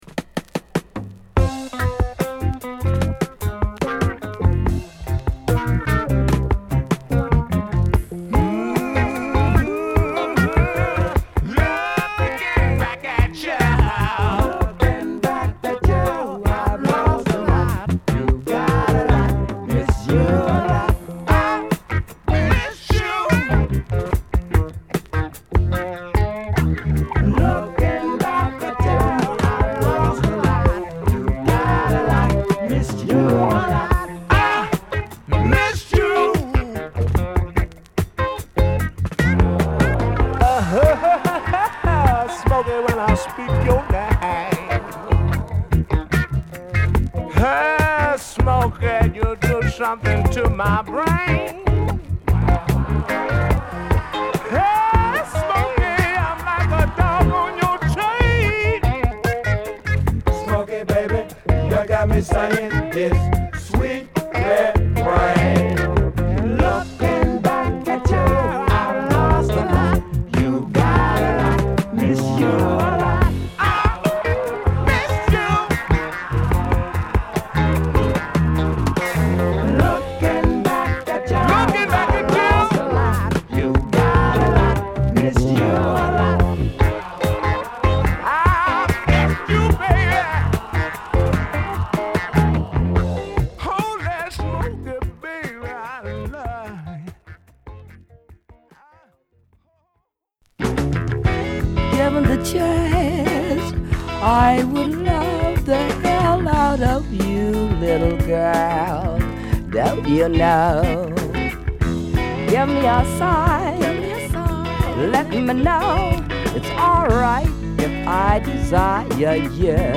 スロウテンポで粘着度の高いドラム＆ギターに呪術的なコーラスと気だるいヴォーカルが煙たく絡む、P-Funkチューン！！
爽やかでメロウなトラックにクセのあるヴォーカルが乗るモダンなP-Funk